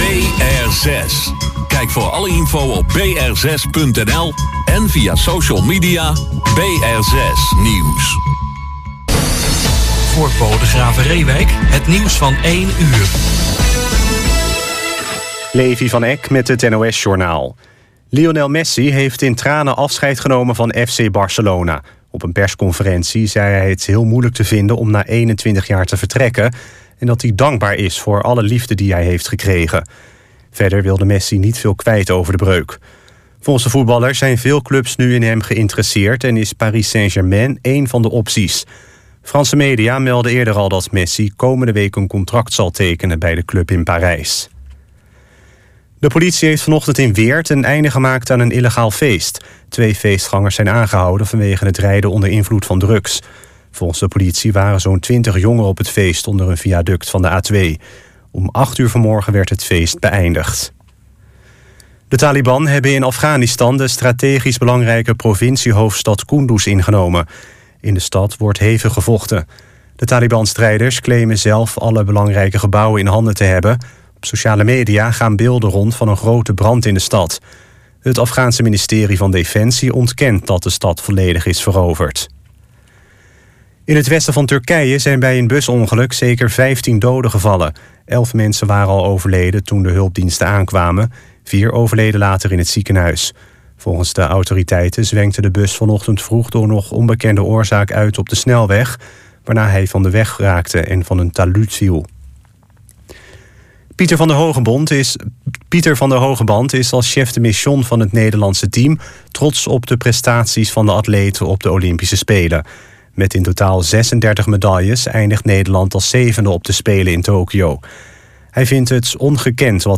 “Jukebox” wordt elke zondagmiddag uitgezonden via BR6, van 13:00 tot 14:00 uur en ’s avonds van 22:00 tot 23:00 uur (herhaling).